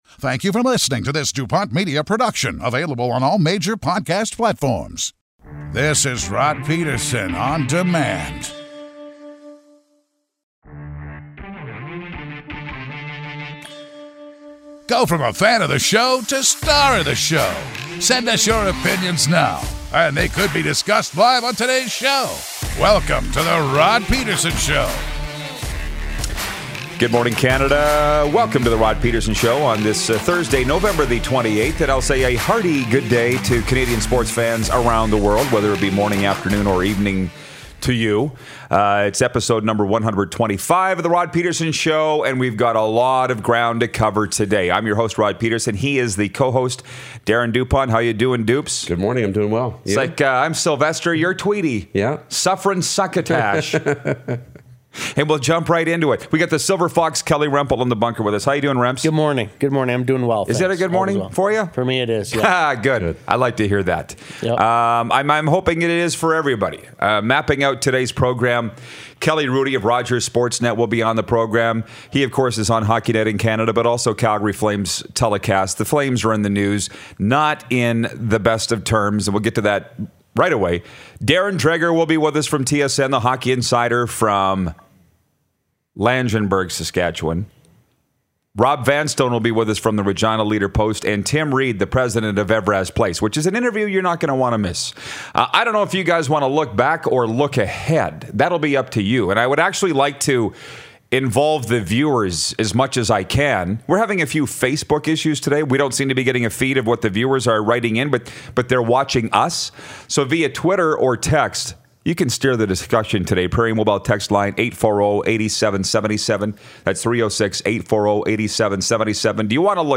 Join us for coffee and the best sports talk around!
TSN Hockey Insider, Darren Dreger gives us a call!